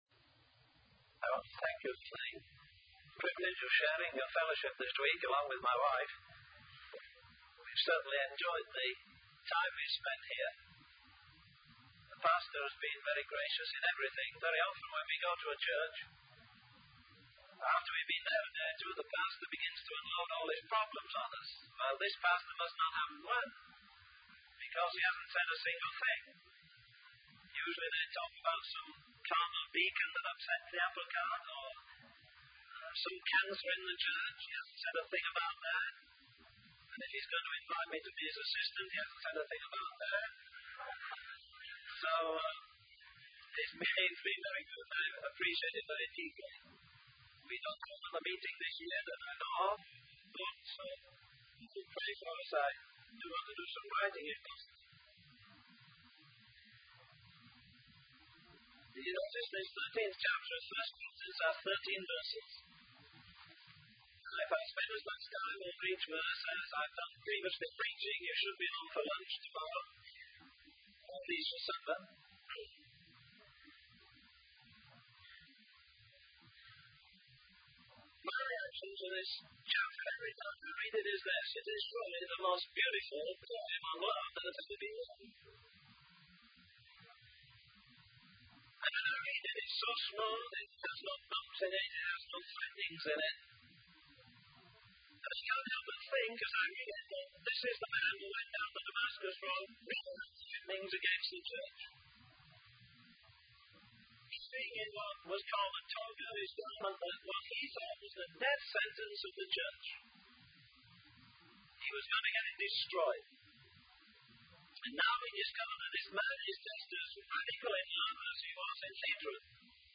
In this sermon, the speaker shares a personal anecdote about his experience with his children and a tennis game. He then transitions to discussing the concept of love, specifically the biblical definition of love. He emphasizes that love is not just a feeling or emotion, but a selfless act of kindness and sacrifice.